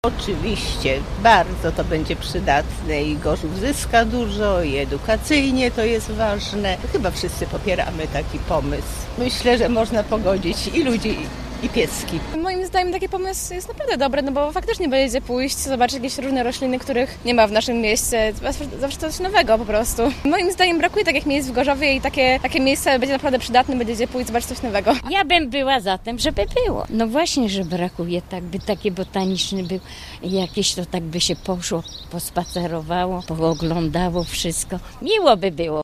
O opinię pytaliśmy także przechodniów: